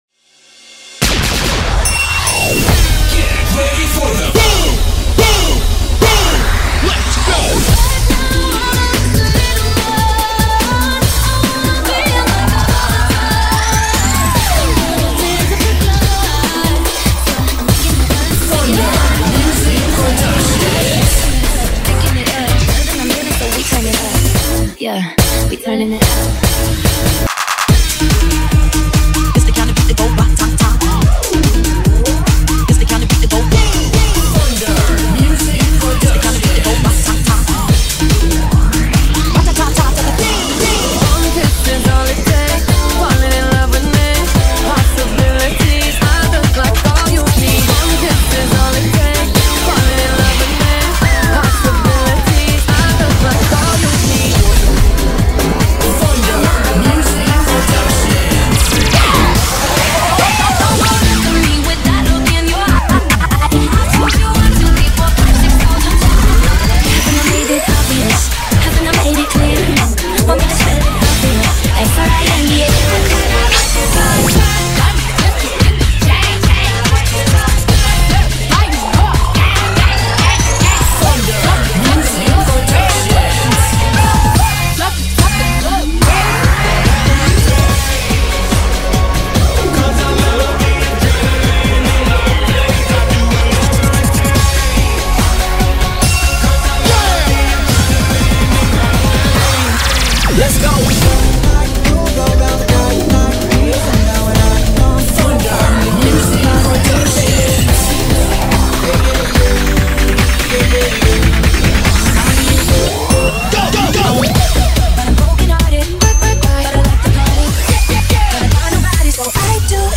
Audio de apoyo  Cheer Mix - BOOM